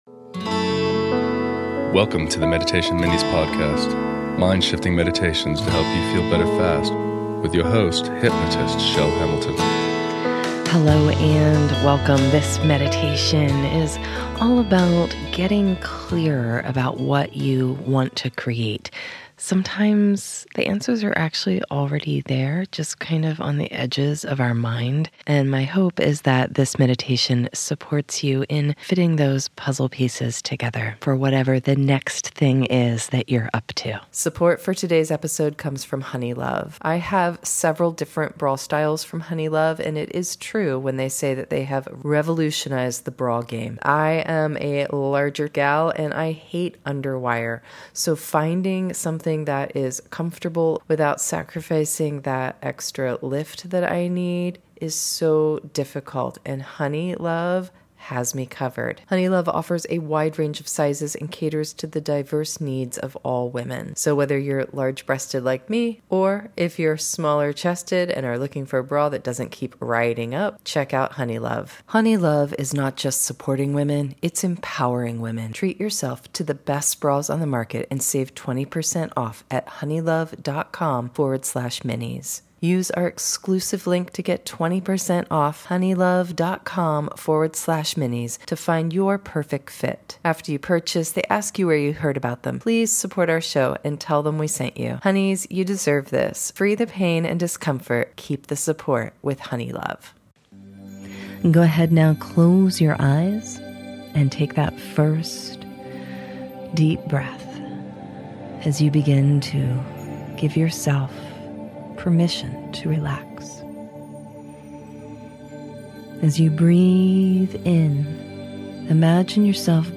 Clarity Meditation Visualization